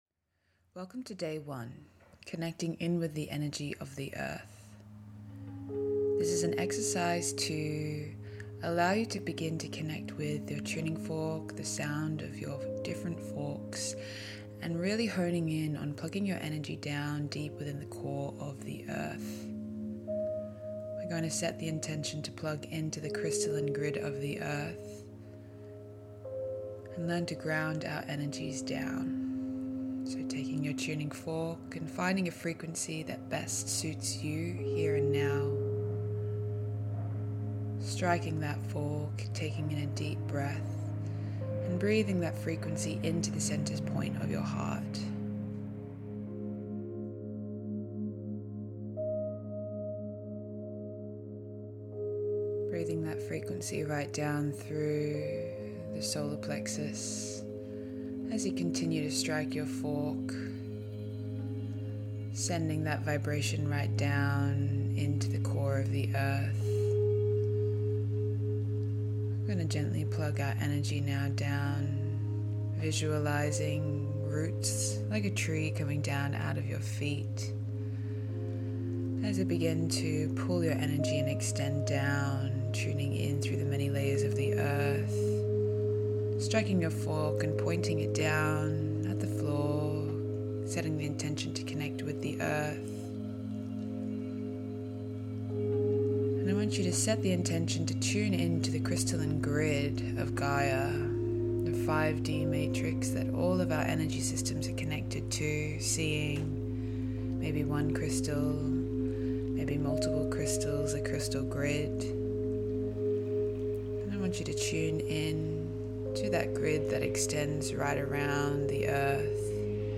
Day 1 - Connect To Earth - Tuning Fork.mp3